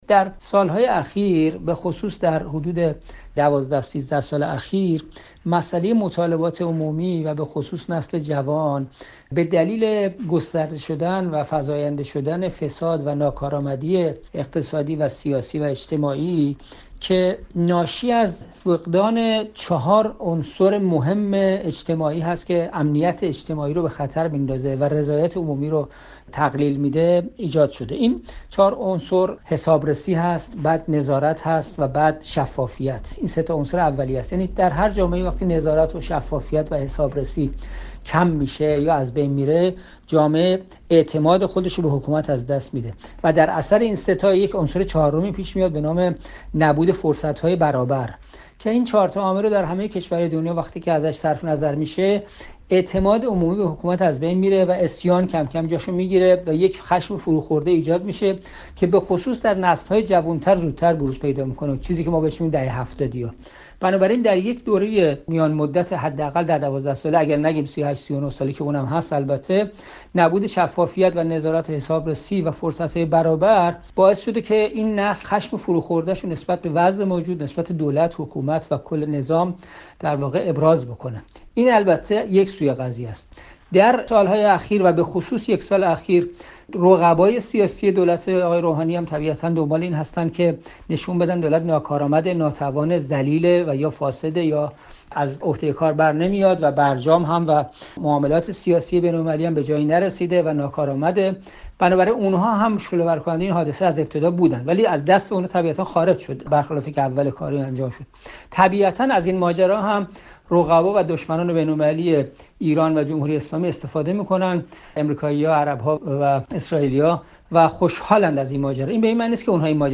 گفتگوی رادیو فردا